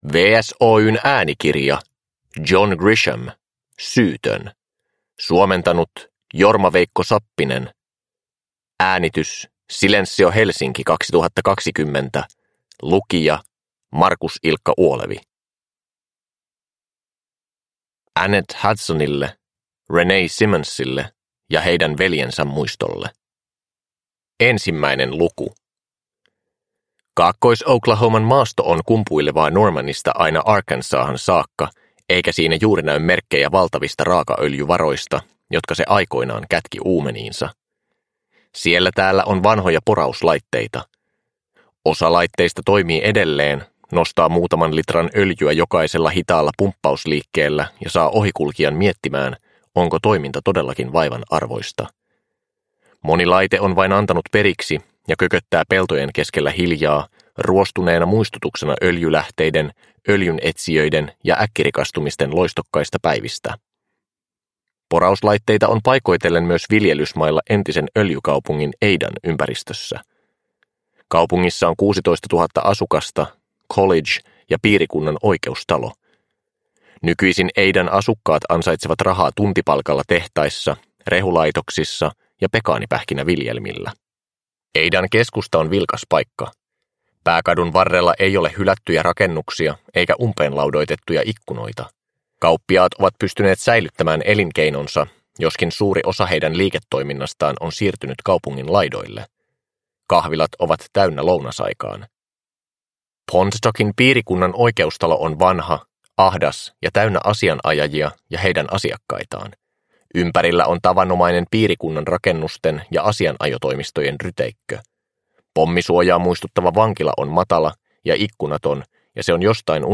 Syytön – Ljudbok – Laddas ner